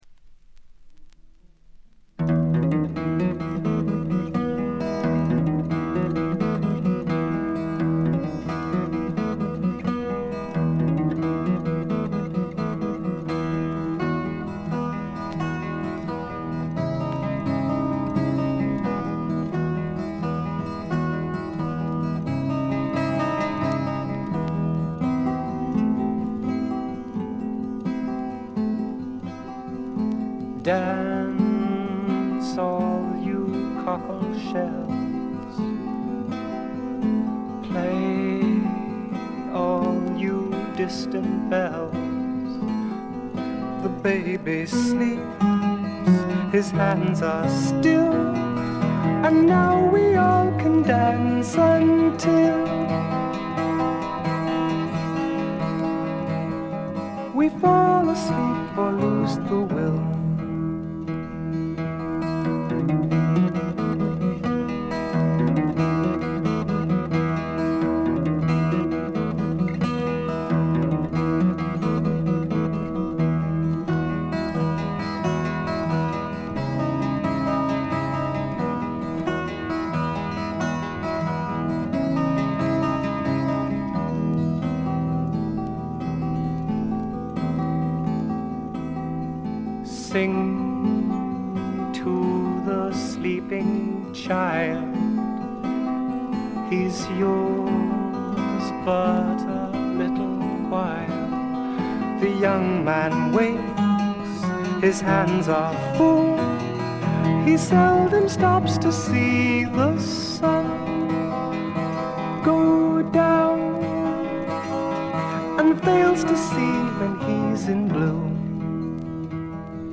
基調は霧の英国フォークでありながらも、幻想的で、ドリーミーで、浮遊感たっぷりで、アシッドな香りも・・・。
試聴曲は現品からの取り込み音源です。